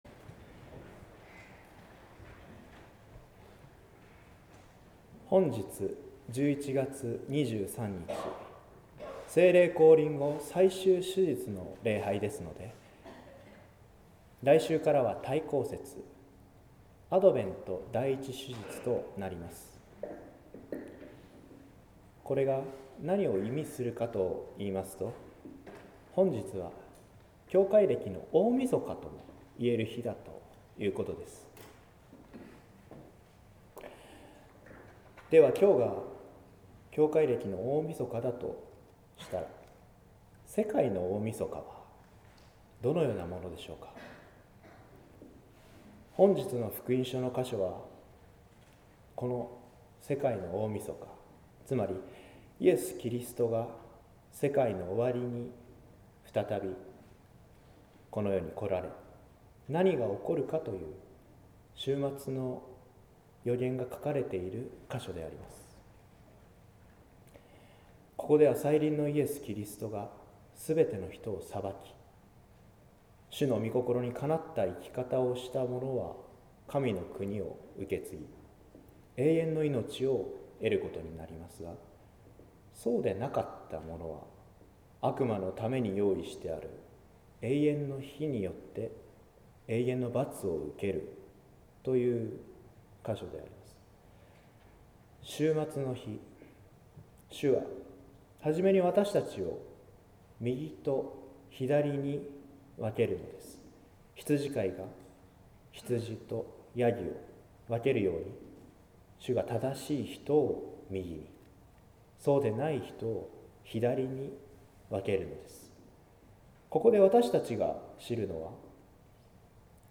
説教「用意された御国」（音声版）